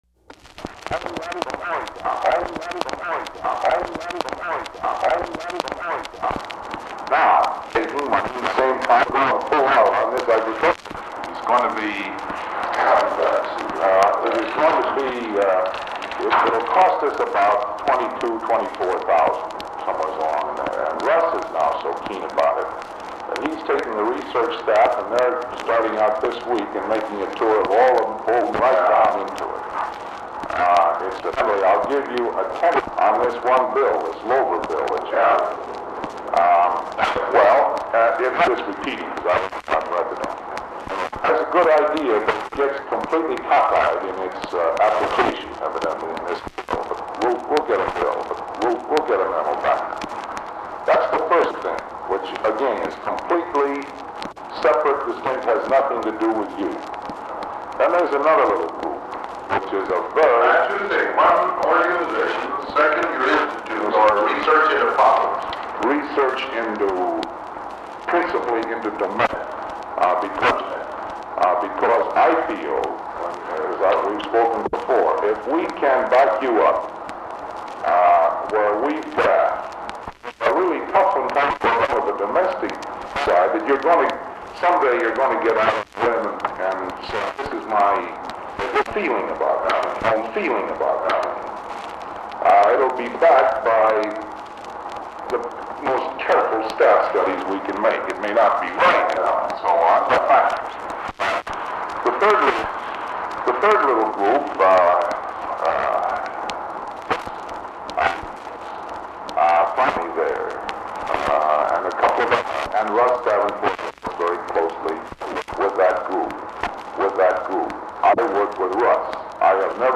Recording has numerous skips and frequently repeats phrases before continuing the conversation.